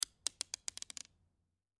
На этой странице собраны разнообразные звуки бус: от легкого перекатывания бусин до их мелодичного звона.
Звук отскока бусины от гладкой поверхности, переливающиеся бусы